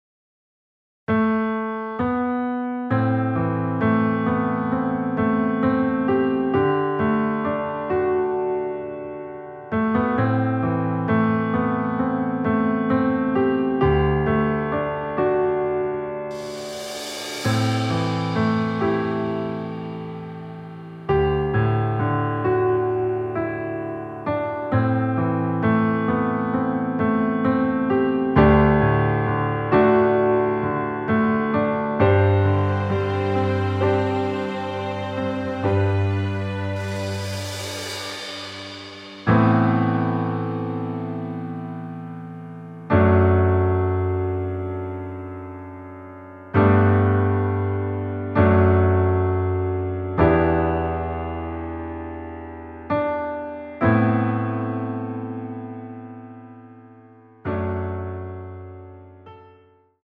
원키에서(+1)올린 MR입니다.
◈ 곡명 옆 (-1)은 반음 내림, (+1)은 반음 올림 입니다.
앞부분30초, 뒷부분30초씩 편집해서 올려 드리고 있습니다.
중간에 음이 끈어지고 다시 나오는 이유는